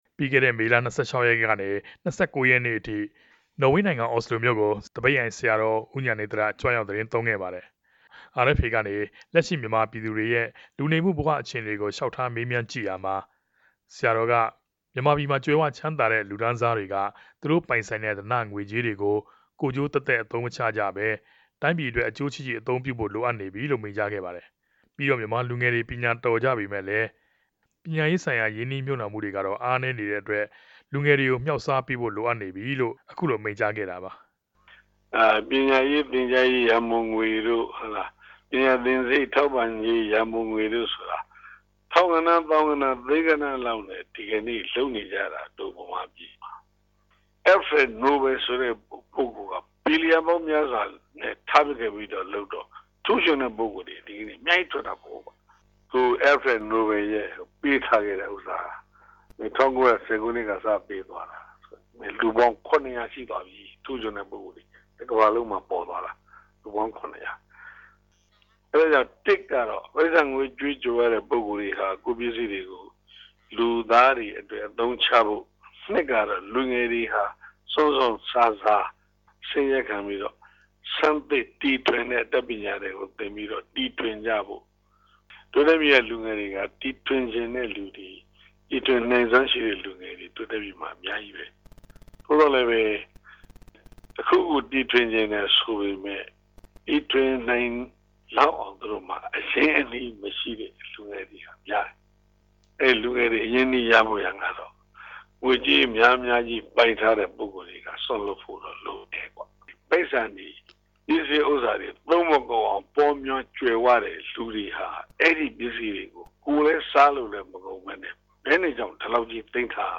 ဆရာတော်ဟာ မုကာသေးခင်ရက်ပိုင်းက နော်ဝေးိံိုင်ငံ အော်စလို္ဘမိြႛကို ဒေသစာရီ ရကြရောက်ခဲ့စဉ်မြာ ိံိုင်ငံတကာ အတြေႛအုကြံတေနြဲ့ ိံိြင်းယြဉ်္ဘပီး အခုလို မိန်ႛုကားခဲ့တာပၝ။